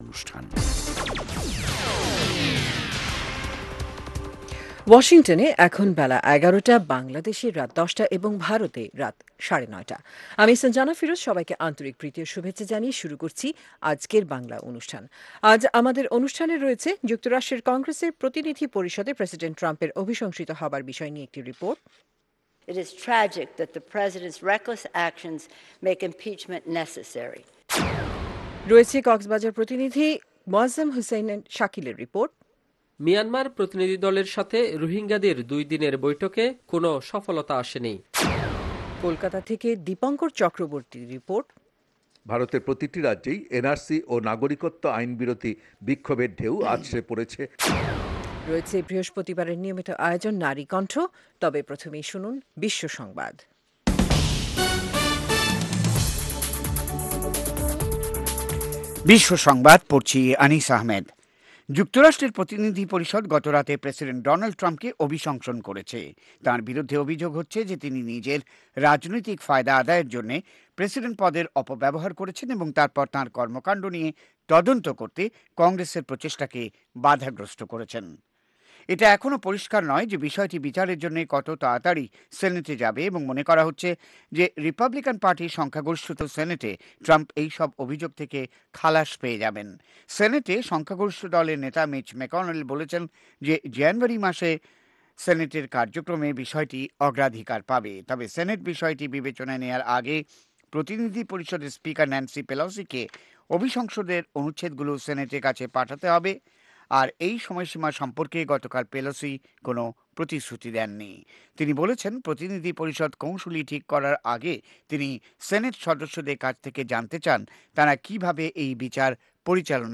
অনুষ্ঠানের শুরুতেই রয়েছে আন্তর্জাতিক খবরসহ আমাদের ঢাকা এবং কলকাতা সংবাদদাতাদের রিপোর্ট সম্বলিত ‘বিশ্ব সংবাদ’, এর পর রয়েছে ওয়ার্ল্ড উইন্ডোতে আন্তর্জাতিক প্রসংগ, বিজ্ঞান জগত, যুব সংবাদ, শ্রোতাদের চিঠি পত্রের জবাবের অনুষ্ঠান 'মিতালী' এবং আমাদের অনুষ্ঠানের শেষ পর্বে রয়েছে যথারীতি সংক্ষিপ্ত সংস্করণে বিশ্ব সংবাদ।